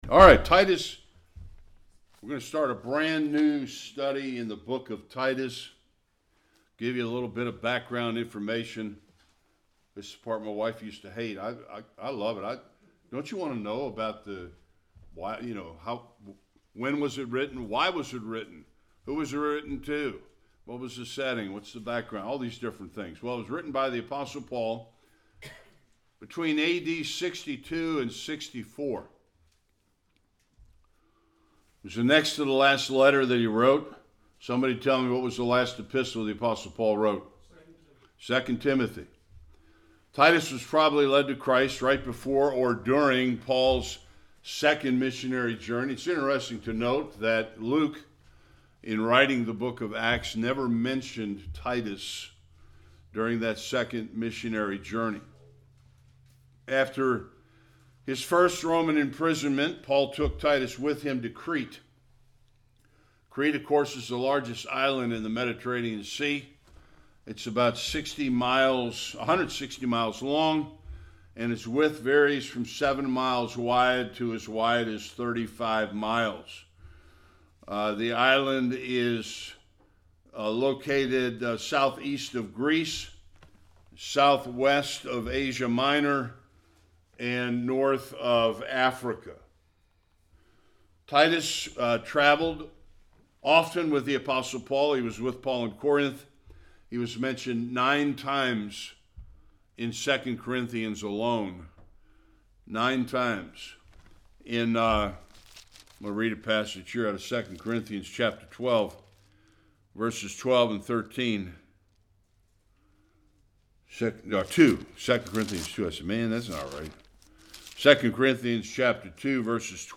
1-2 Service Type: Sunday Worship Introduction to Titus and his responsibilities.